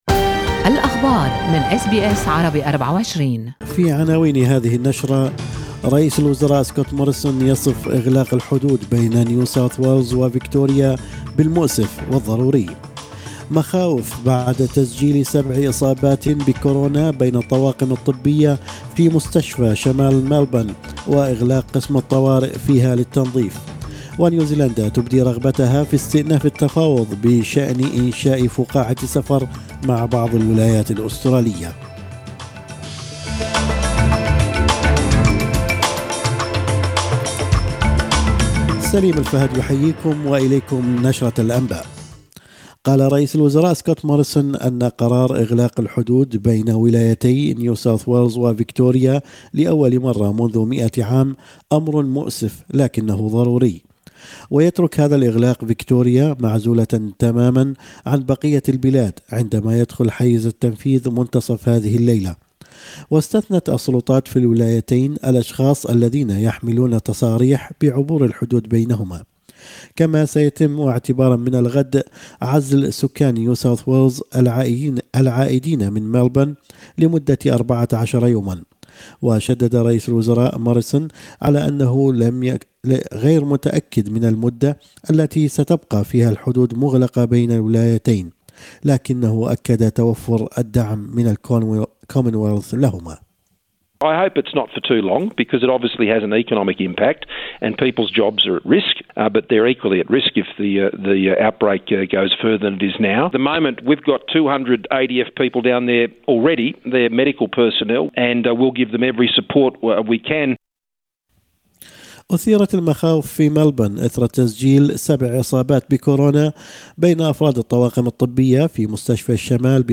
نشرة أخبار الصباح 7/7/2020